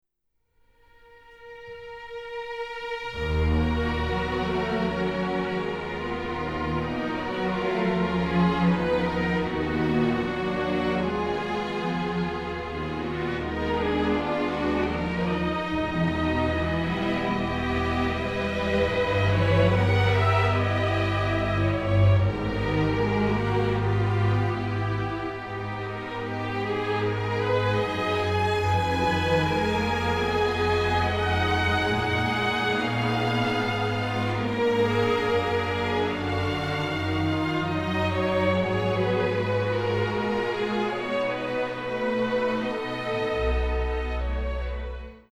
delightfully emotional
full of beautiful and delicate melodies